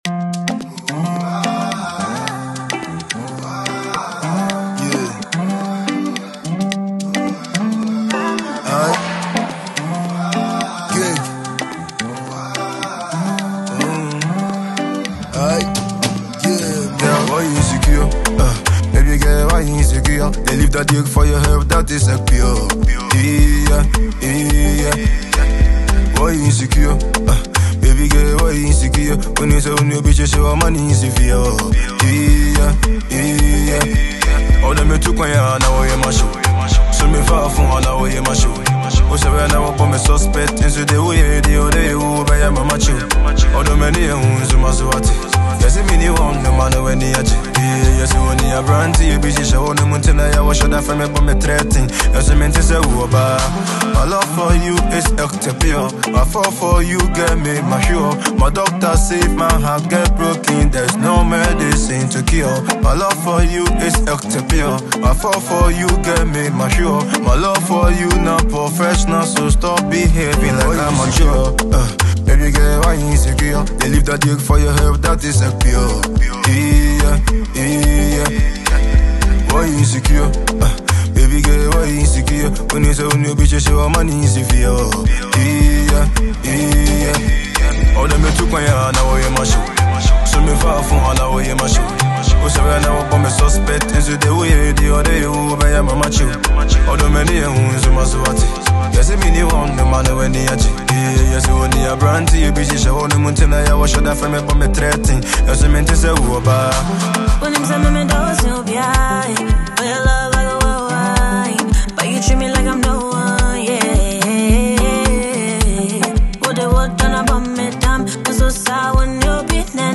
a Ghanaian trapper
asakaa rapper
Enjoy this Ghana Afrobeat tune.